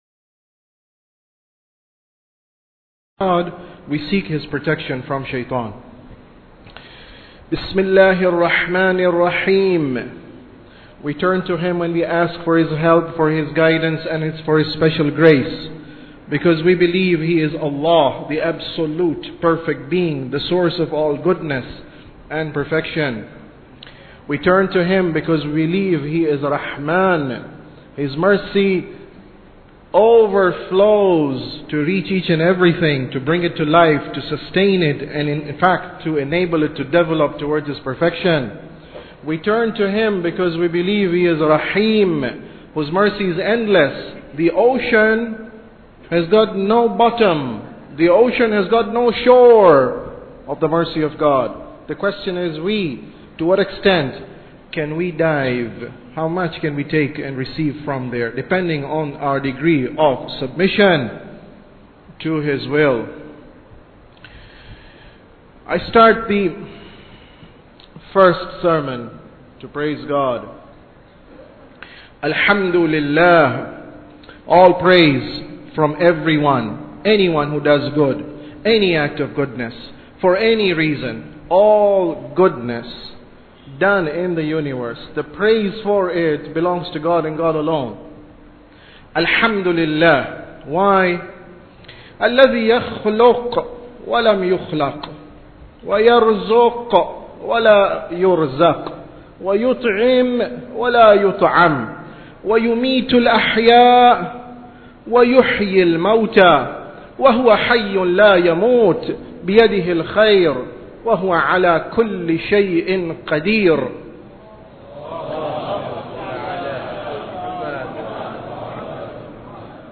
Sermon About Tawheed 6